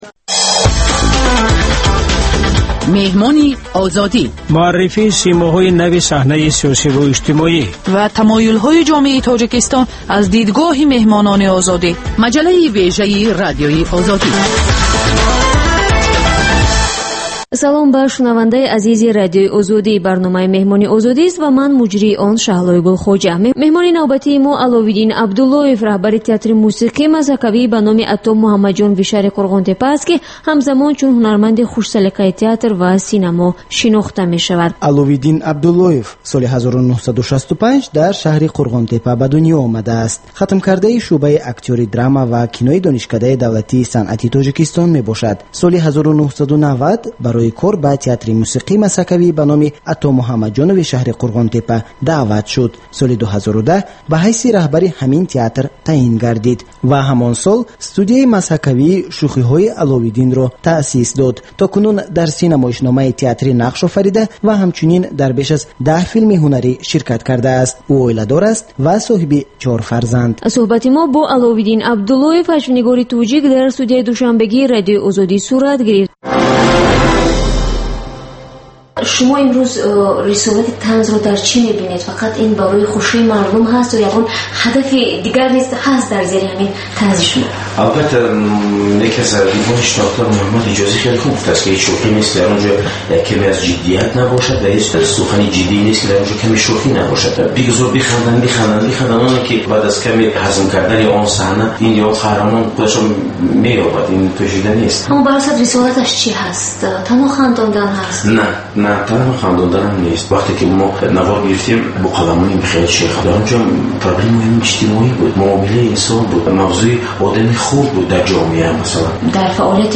Гуфтугӯи ошкоро бо шахсони саршинос ва мӯътабари Тоҷикистон.